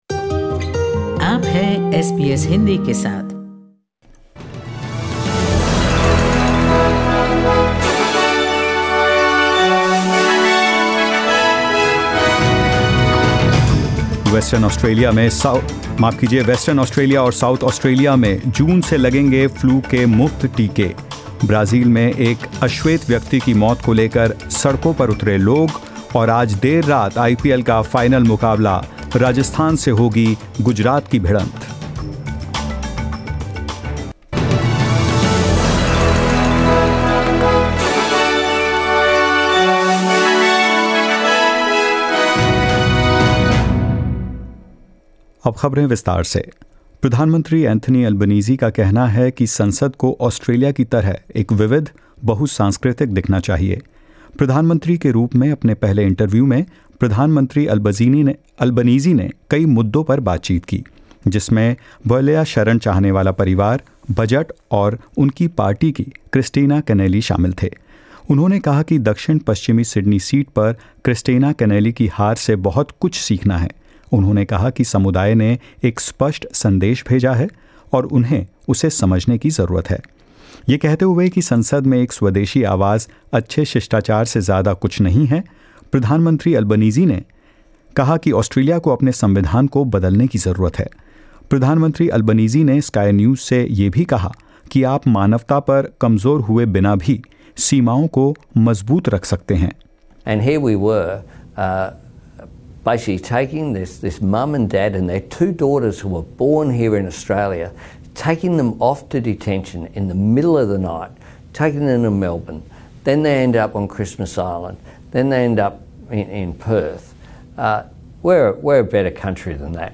In this latest SBS Hindi bulletin: Prime Minister Anthony Albanese quizzed on the Biloela asylum seeker family, Kristina Kenneally's loss in Fowler, and multiculturalism; IPL 2022 final set to be played tonight, and more